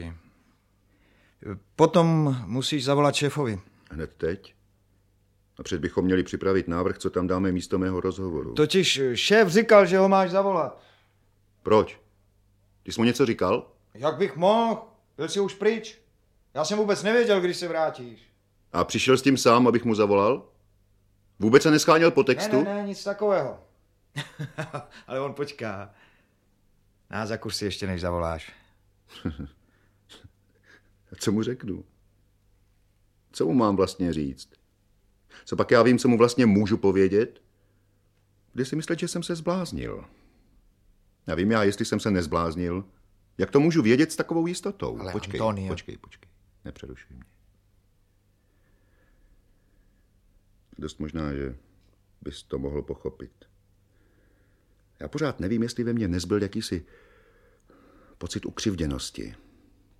Audiobook
Audiobooks » Short Stories